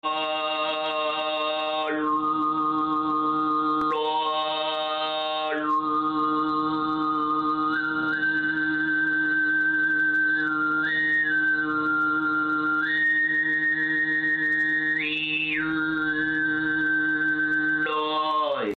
신기한 몽골의 전통소리 우와 어떻게 Sound Effects Free Download